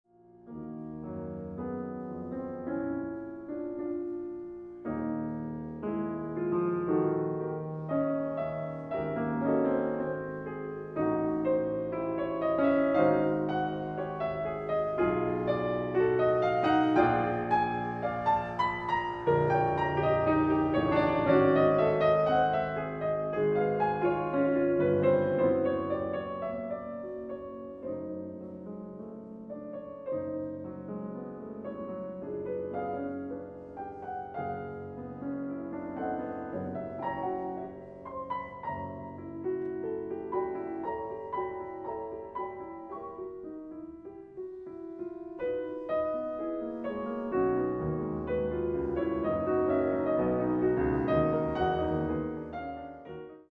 arr. for 2 pianos